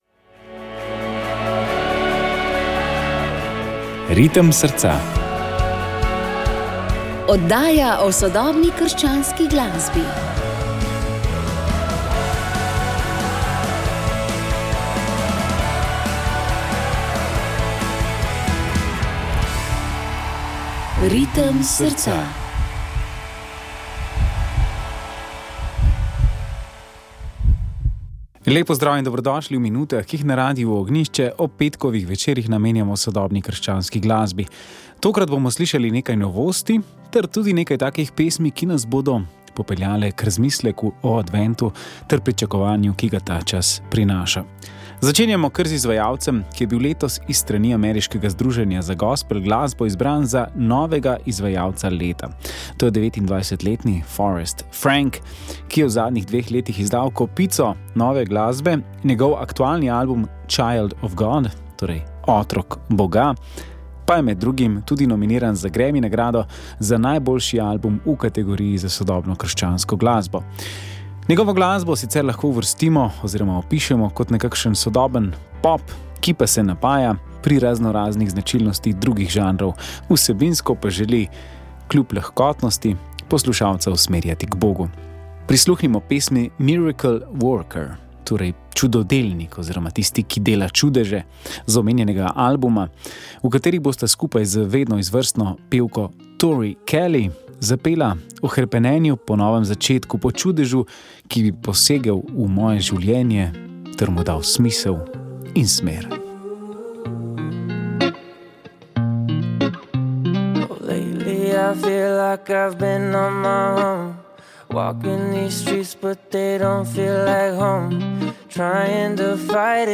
sodobna krščanska glasba